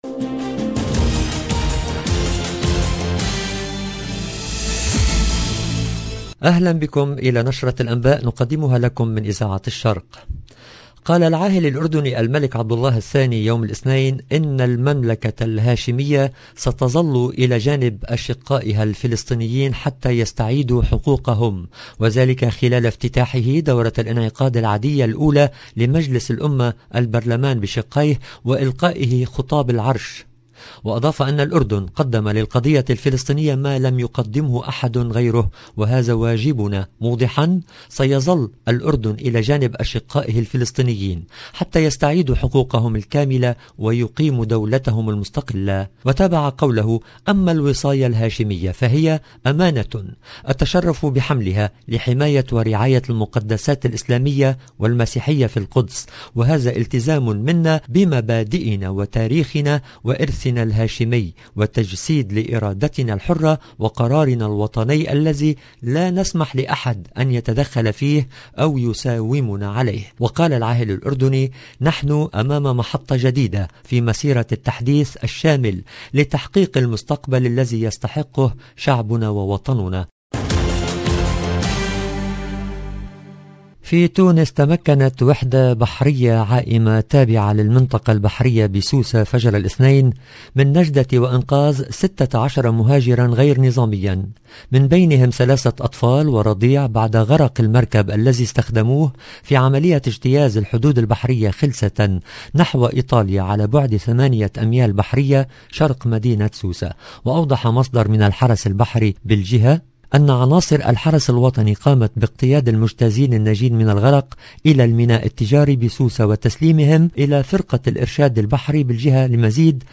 LE JOURNAL DU SOIR EN LANGUE ARABE DU 15/11/21